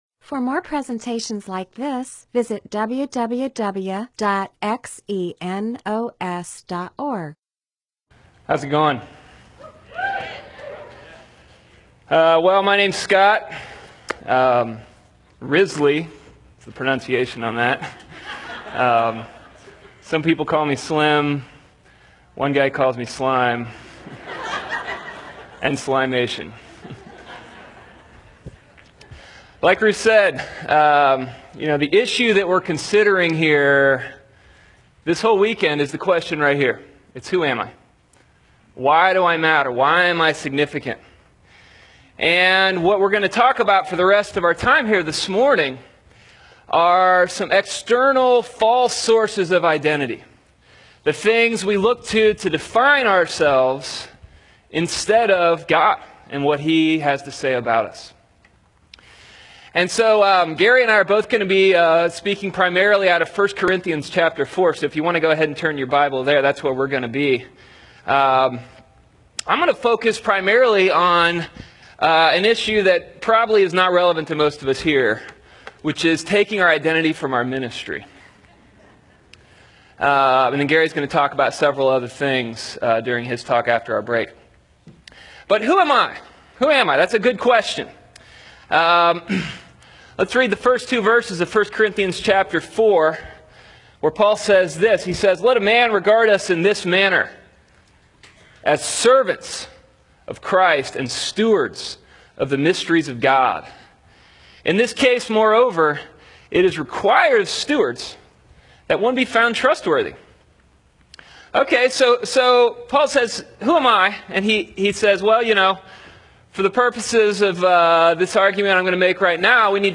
MP4/M4A audio recording of a Bible teaching/sermon/presentation about 1 Corinthians 4:1-13.